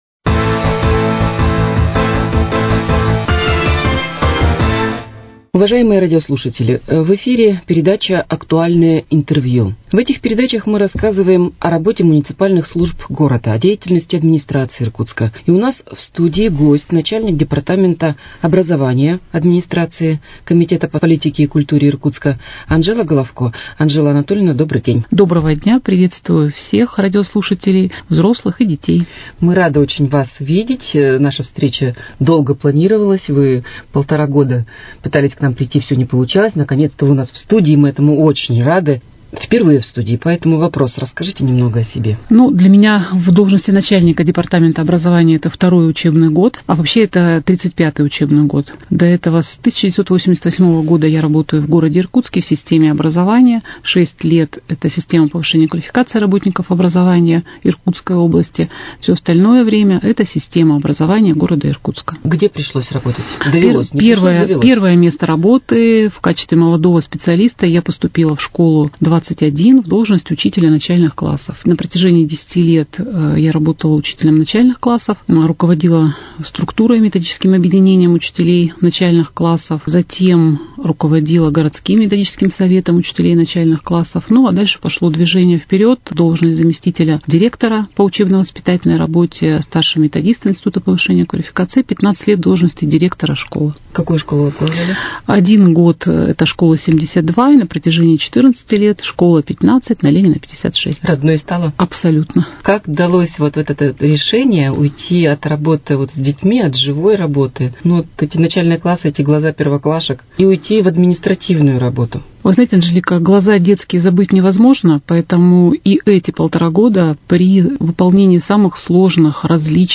Актуальное интервью: Беседа с начальником департамента образования Комитета по социальной политике и культуре администрации г.Иркутска Анжелой Головко